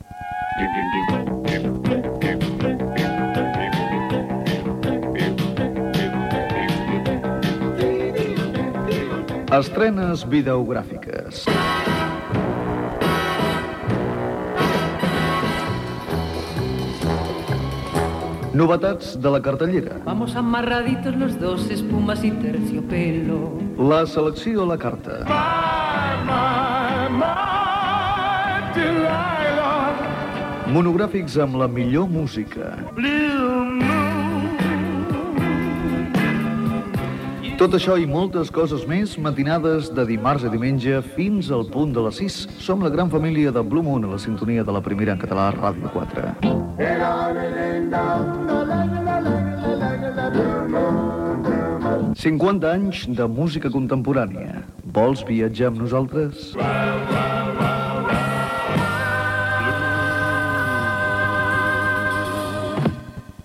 Promoció del programa Gènere radiofònic Musical